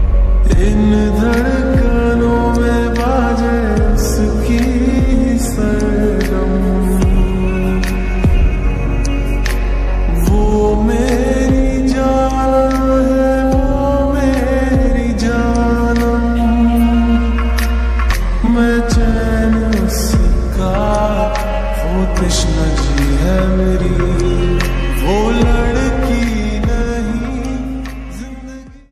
Song Ringtones